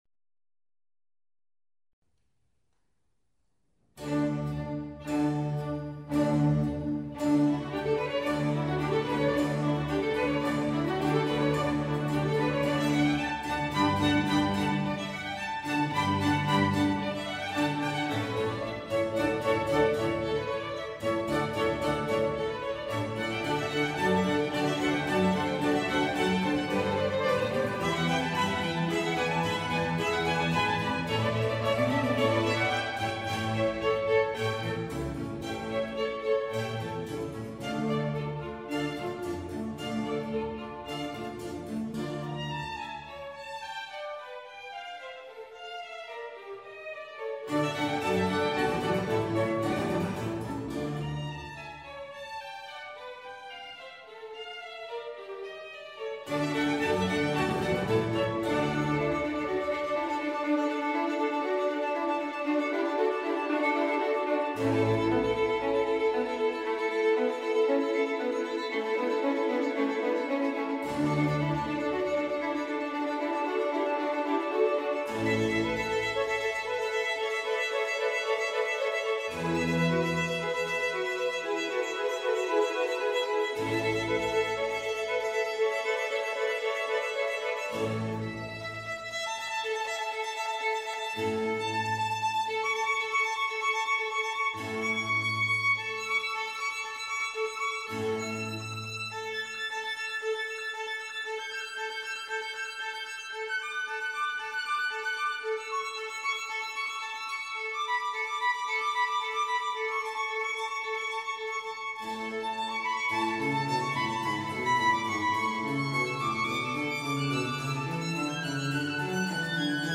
Concerto per violino e archi
violino solista